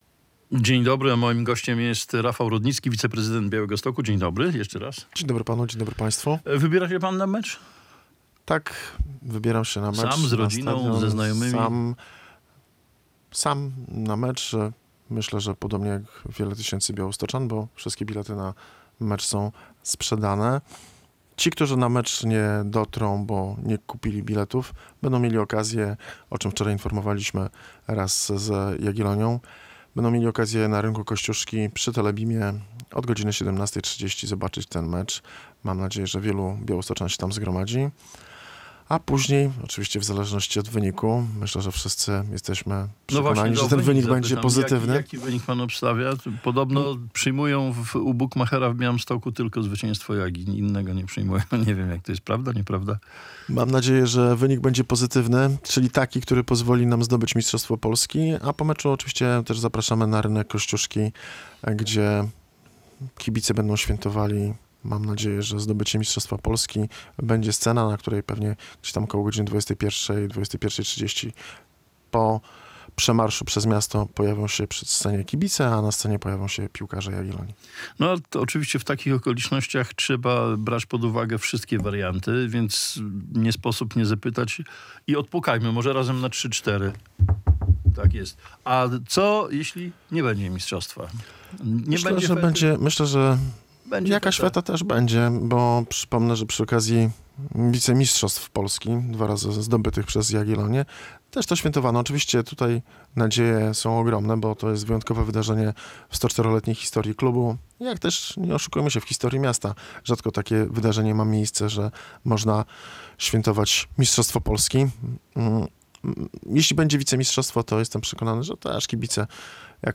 Radio Białystok | Gość | Rafał Rudnicki [wideo] - zastępca prezydenta Białegostoku